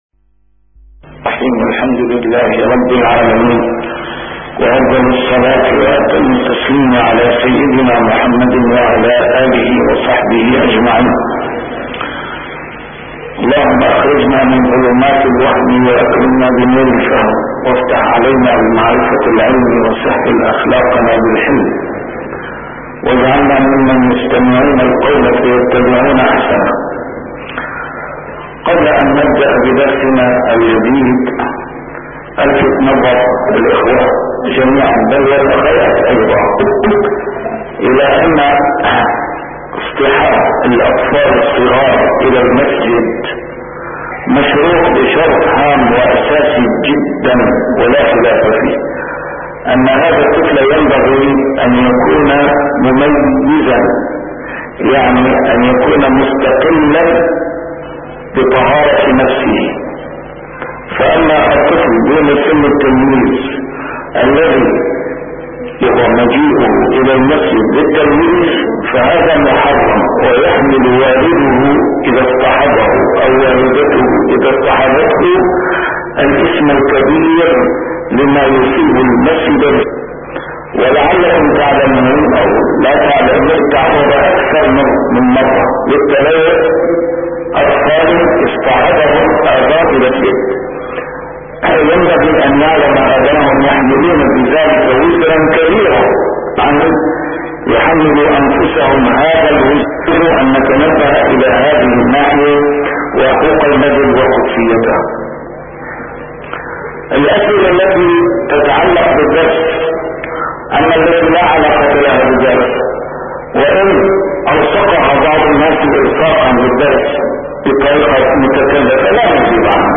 A MARTYR SCHOLAR: IMAM MUHAMMAD SAEED RAMADAN AL-BOUTI - الدروس العلمية - شرح كتاب رياض الصالحين - 708- شرح رياض الصالحين: تلقين المحتضر